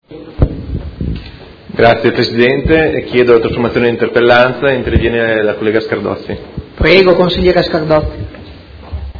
Marco Rabboni — Sito Audio Consiglio Comunale